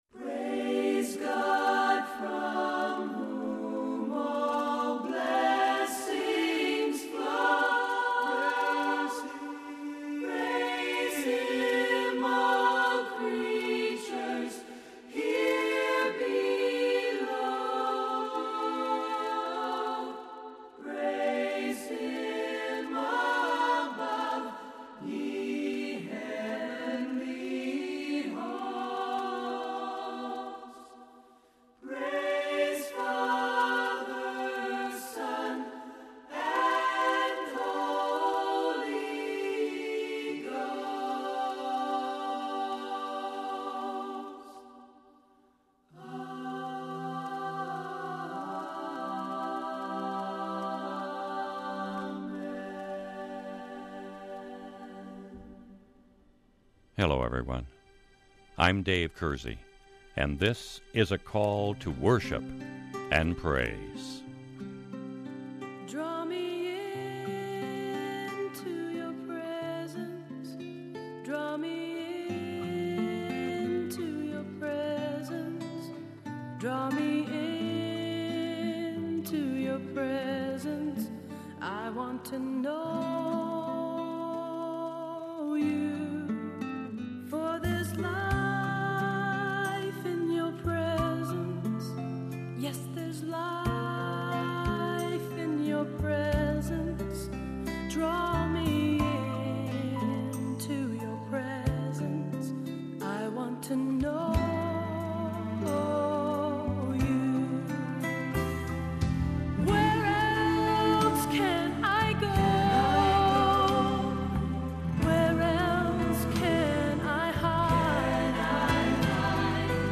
This week’s Devotional Topic This week on our Call To Worship program we will be singing and talking about “Trust or Consequences” by Dr. Charles Stanley.